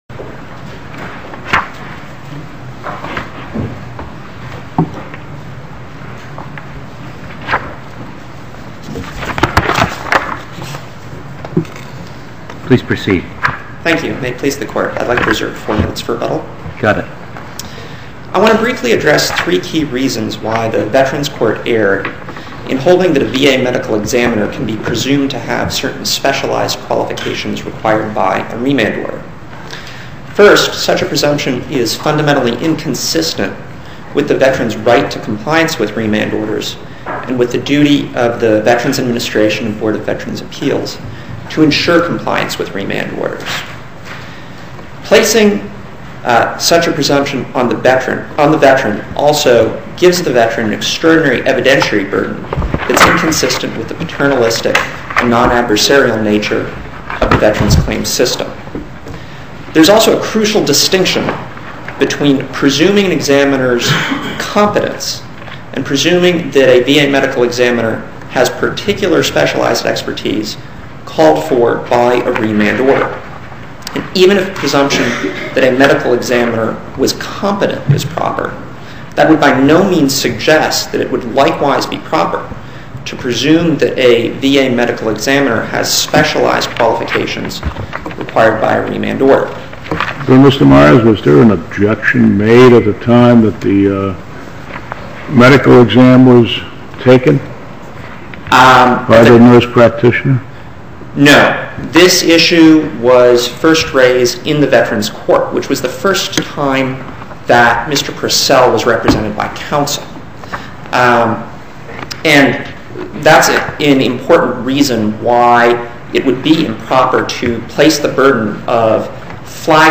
Oral argument audio posted: